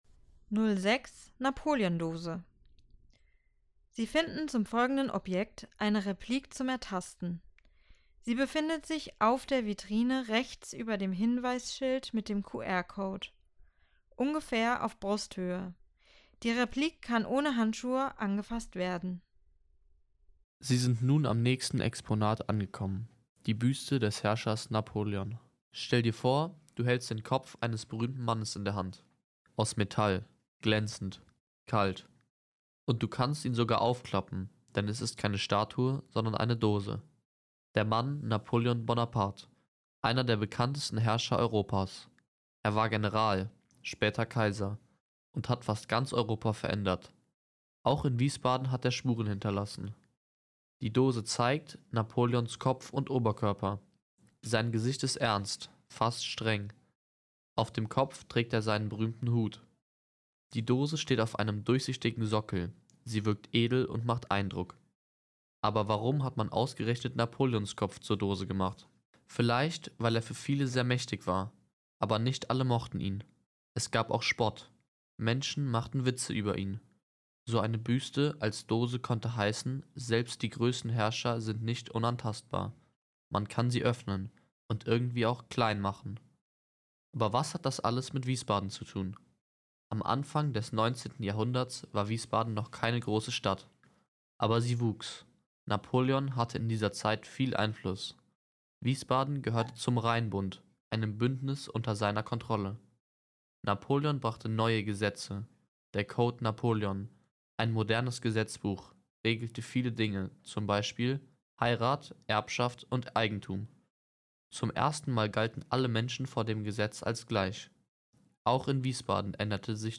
Audioguide - Station 6 Büste Napoleons als Dose